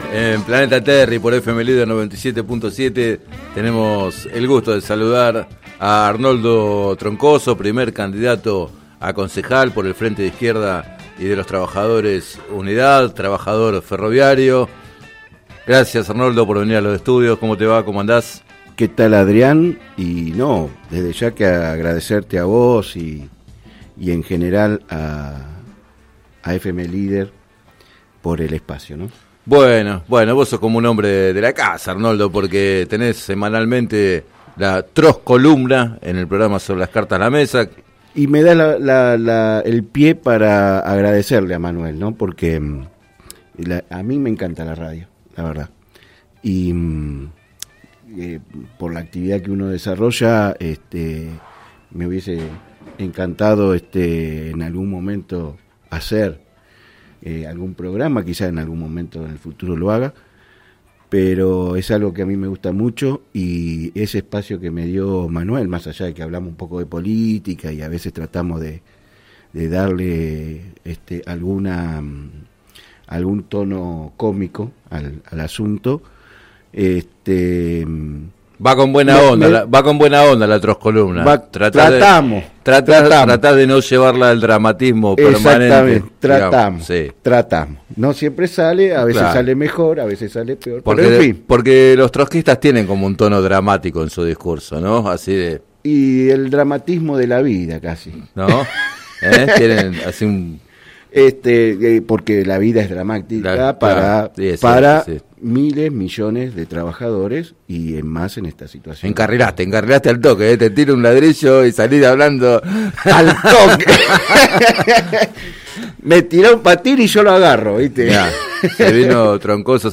Entrevistado en el programa “Planeta Terri” de FM Líder 97.7